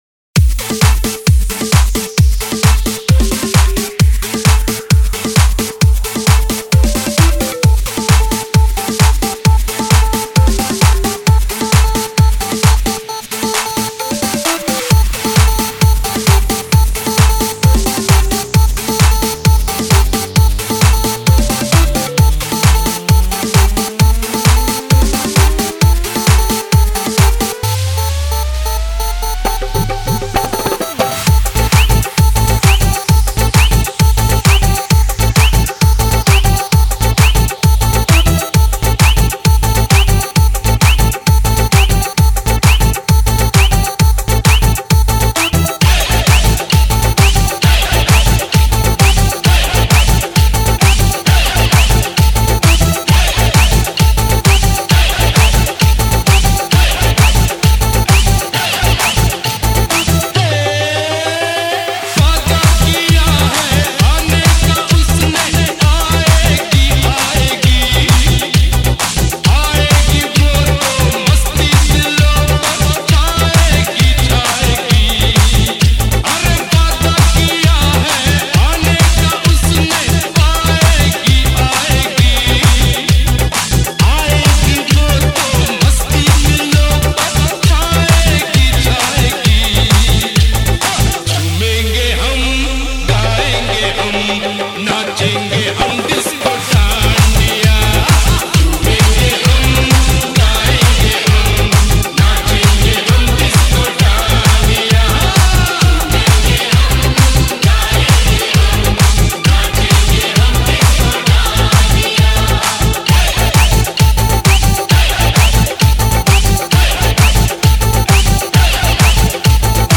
DJ Remix Mp3 Songs > Single Mixes